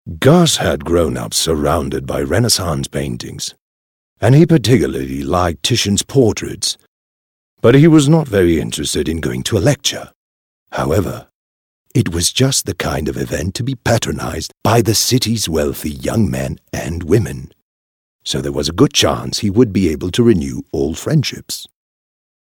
Audio Book Voice Over Narrators
Spanish (Latin American)
Adult (30-50) | Older Sound (50+)